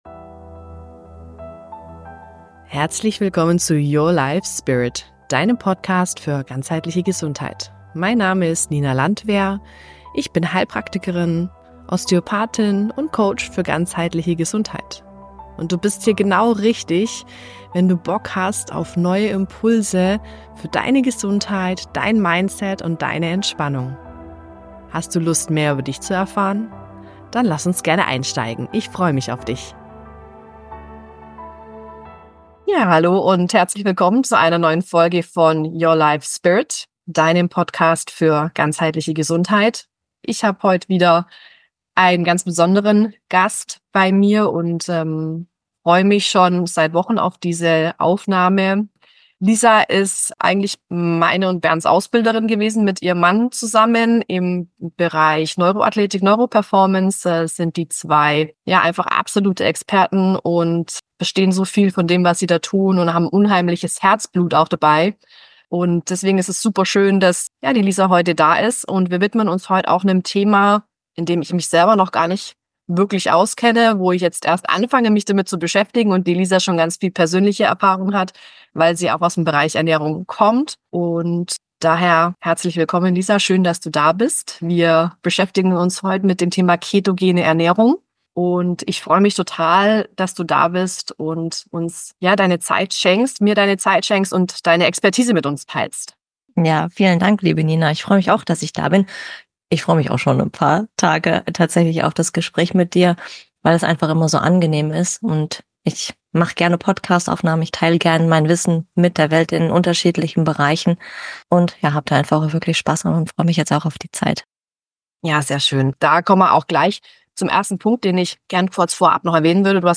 ZUSATZ: Leider gab es bei der Aufnahme Schwierigkeiten mit der Internetverbindung, so dass manche Passagen hängen bzw. schlechter zu verstehen sind.